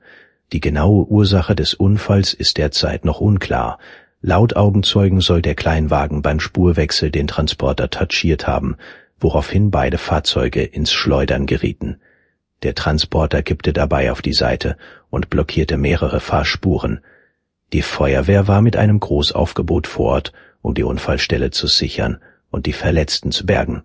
Here a unseen sample with a news article and a speaker from the training data: